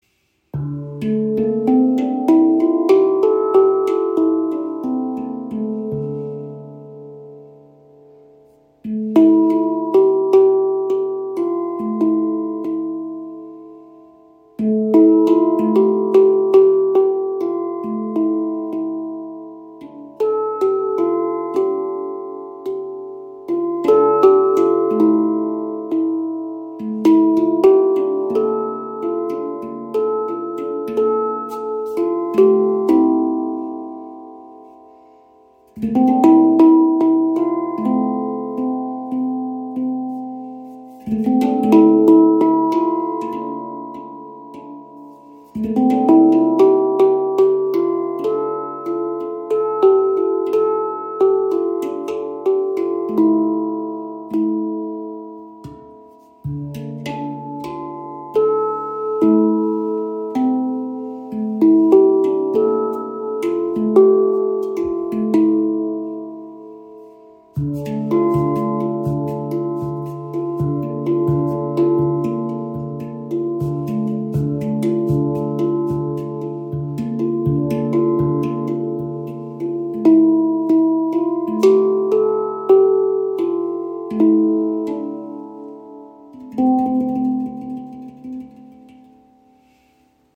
Klangbeispiel
Die D Kurd ist eine sehr harmonische Stimmung, die sich sehr vielseitig einsetzen lässt.